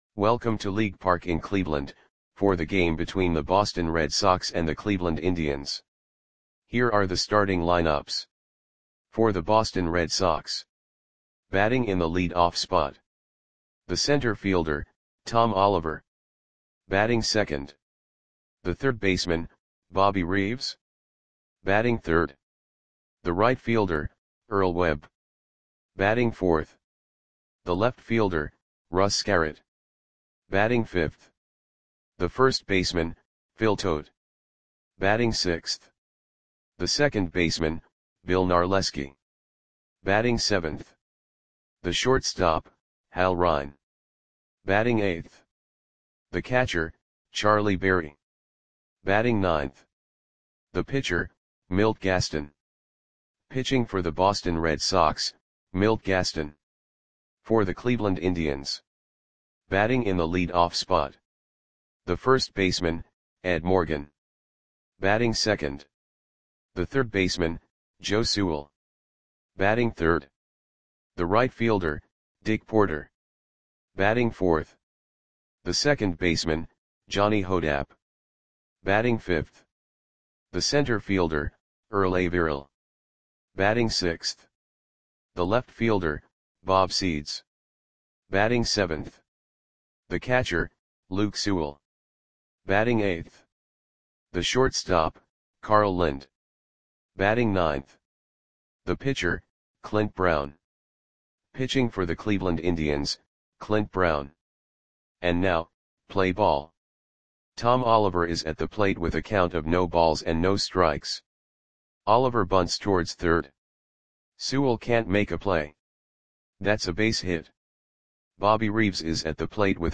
Audio Play-by-Play for Cleveland Indians on June 5, 1930
Click the button below to listen to the audio play-by-play.